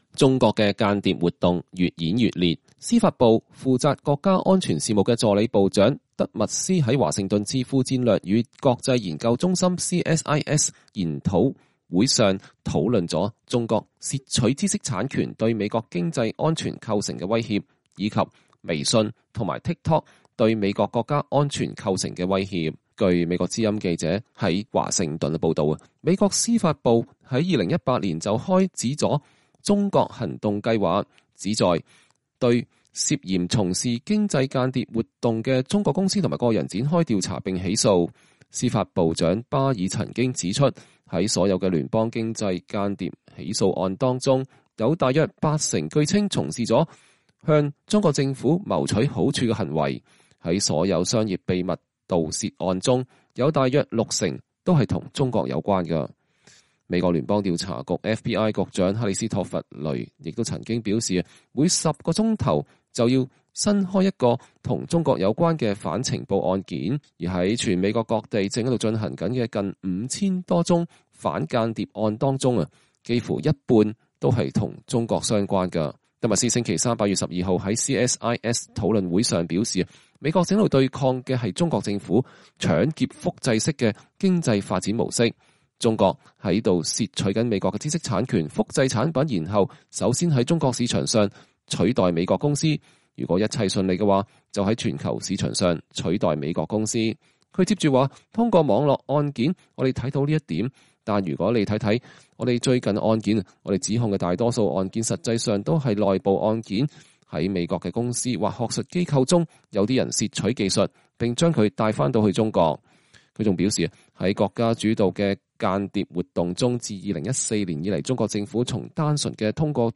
美國司法部國家安全事務助理部長德默斯（John C. Demers）2020年8月12日出席戰略與國際研究中心(CSIS)主辦的一場視訊會（CSIS網站）